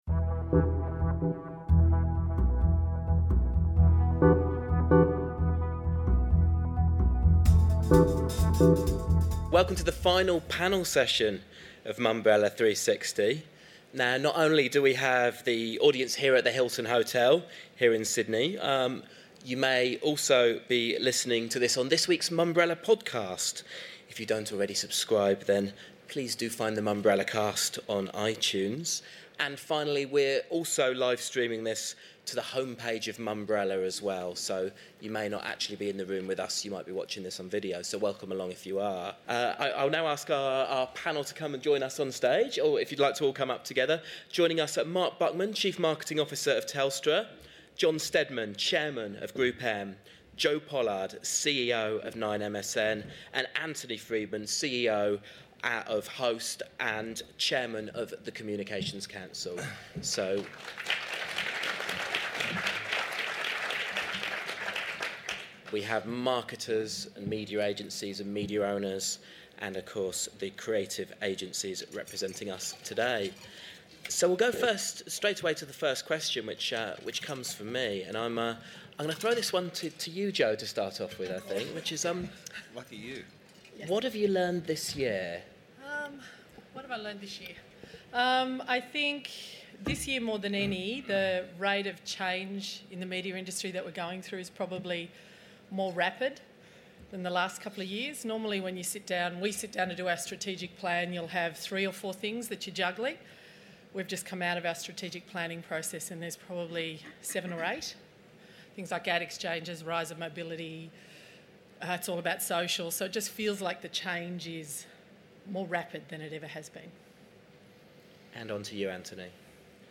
Mumbrella360 Question Time